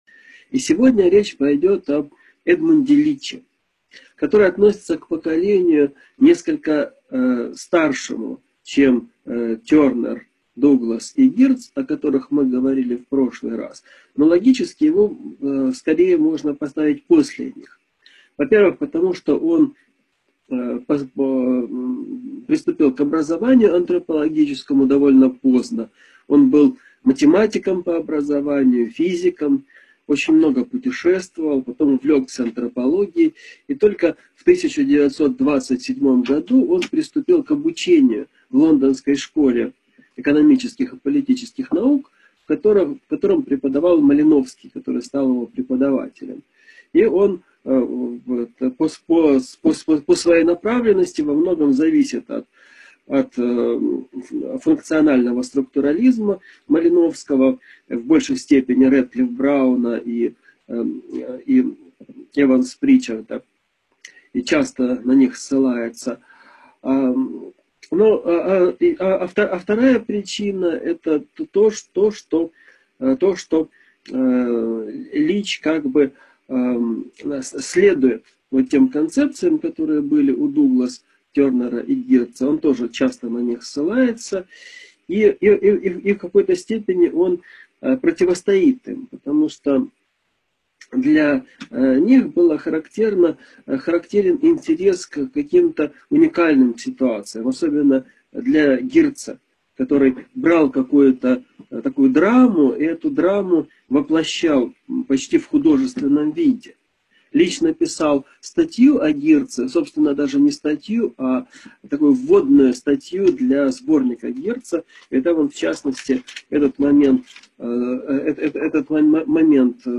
Аудиокнига Лекция №31 «Эдмунд Лич» | Библиотека аудиокниг